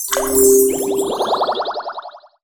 water_bubble_spell_heal_01.wav